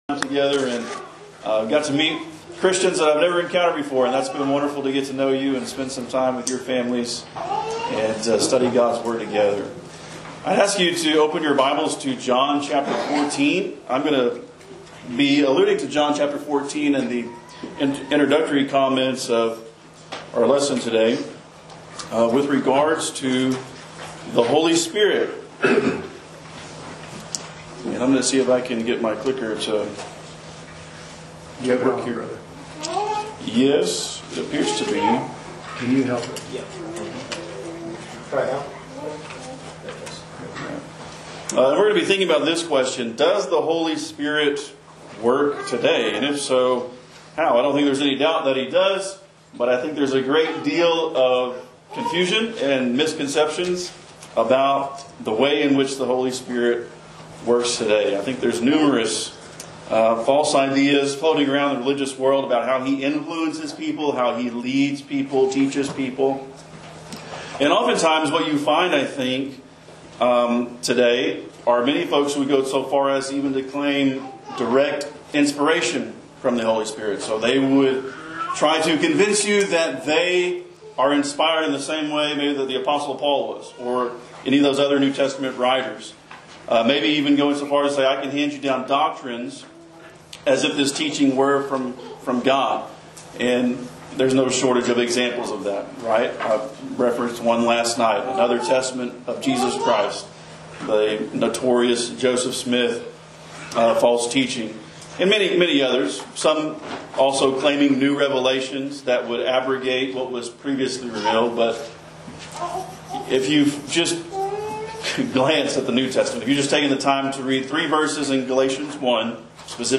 Does The Holy Spirit Work Today?- Gospel meeting